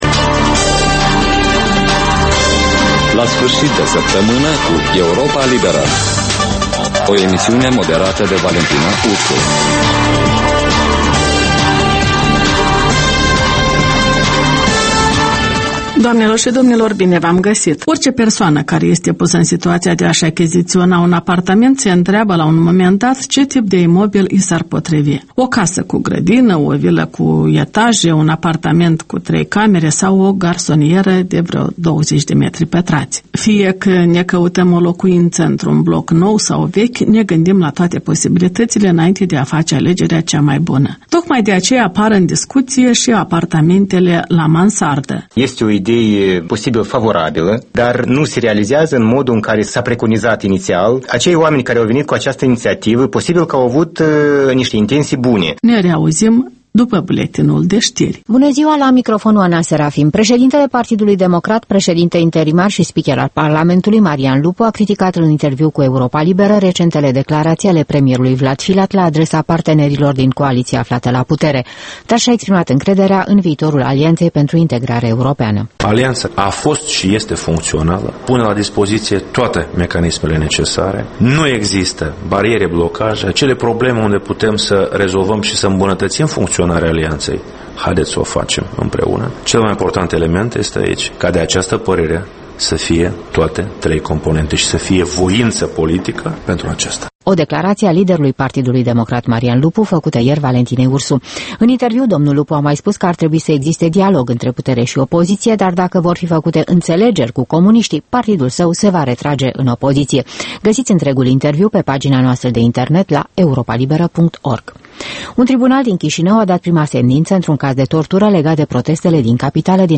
reportaje, interviuri, voci din ţară despre una din temele de actualitate ale săptămînii. In fiecare sîmbătă, un invitat al Europei libere semneaza "Jurnalul săptămînal".